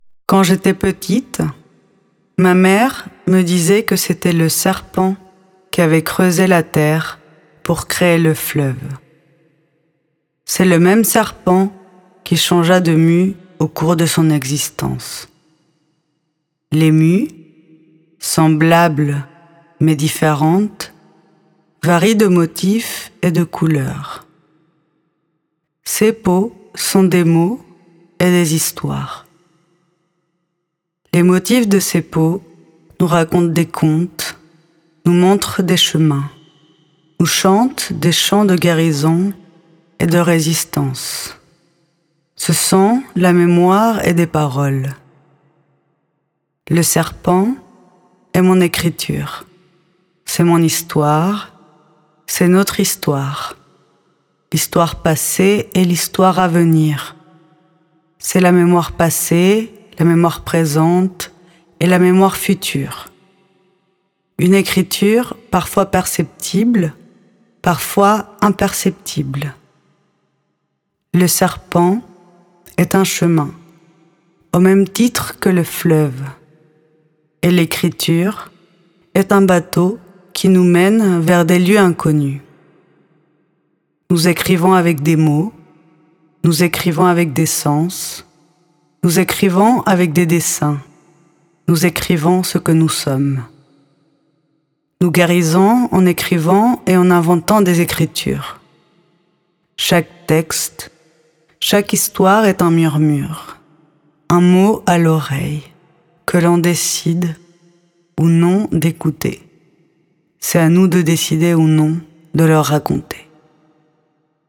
Elle a également enregistré certaines voix du lavoir, que l’on peut écouter via des QR Codes auprès de l’œuvre ou ci-dessous :